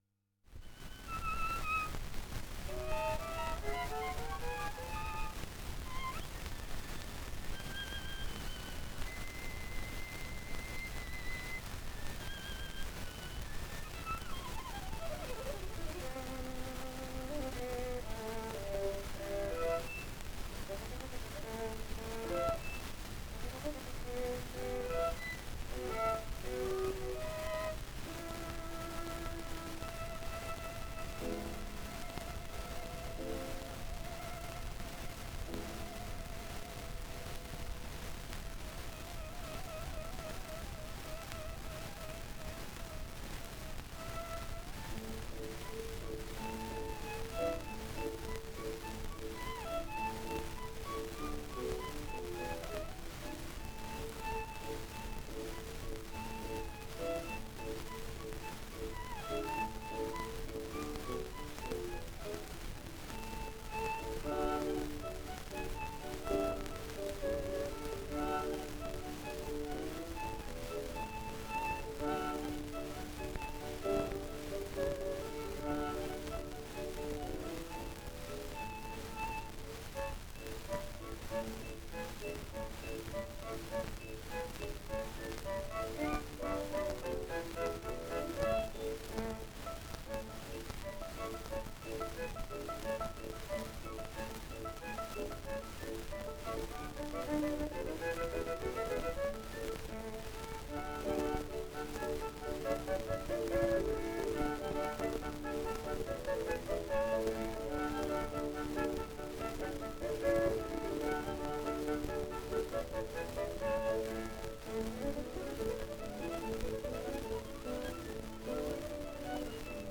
Genre(s) Variations (Violin and piano)
Violin solo
Pianoforte
Place of recording New York (United States)